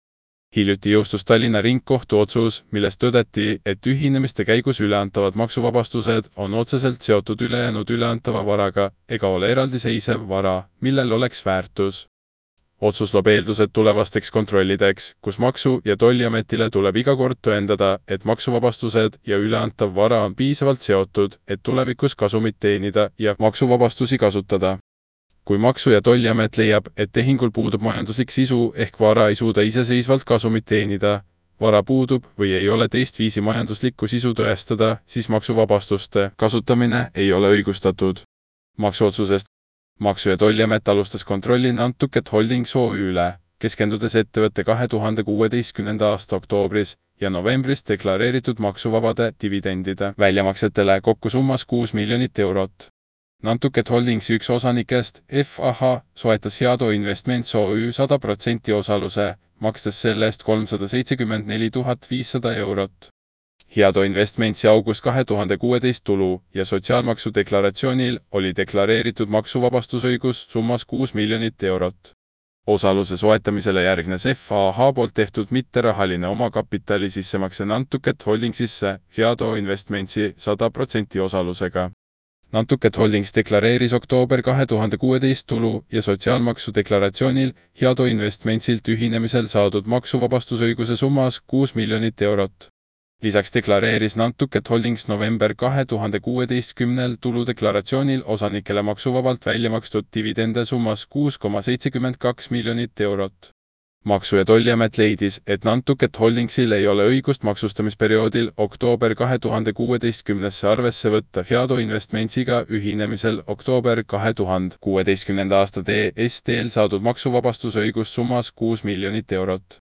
Kui silmad puhkust vajavad, anna artikkel üle kõnerobotile – vajuta ja kuula!